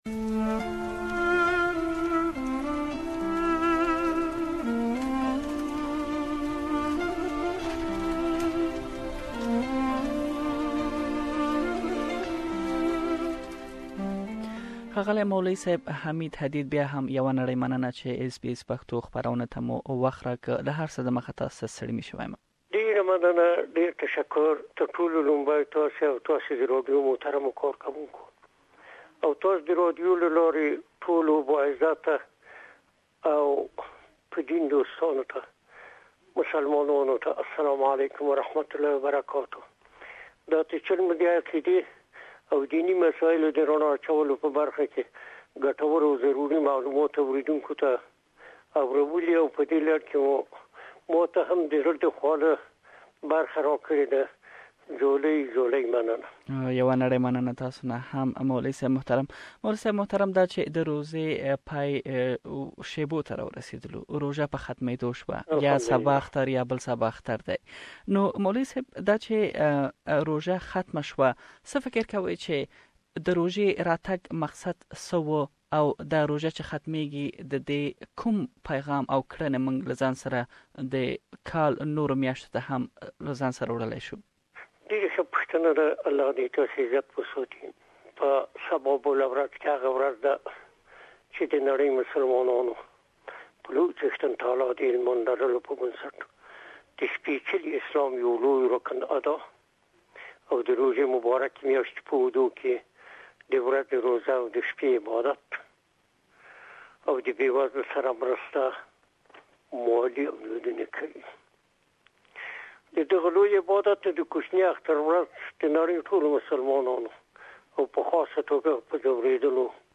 Ramadan is nearly finished, did we ask ourselves what have we achieved and what changes will we have after Ramadan. We have interviewed Sydney based Islamic scholar on the achievements of Ramadan and how to celebrate Eid.